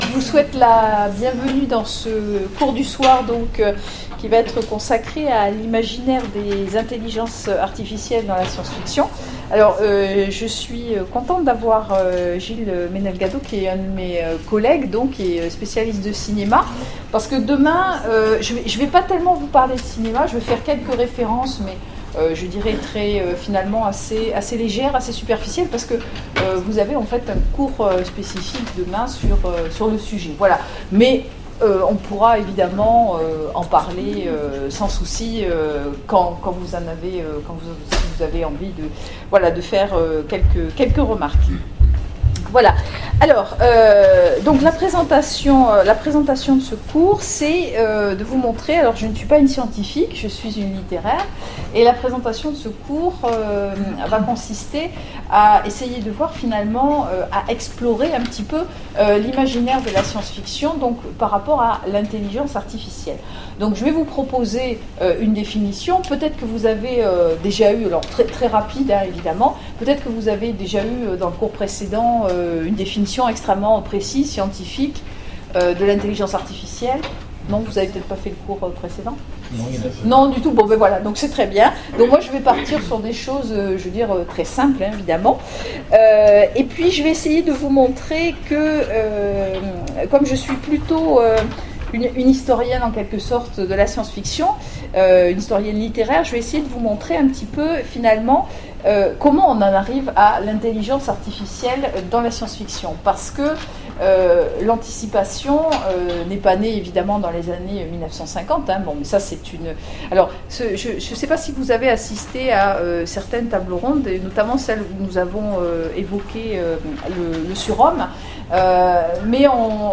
Utopiales 2014 : Cours du soir - Imaginaire des intelligences artificielles dans la science-fiction
Conférence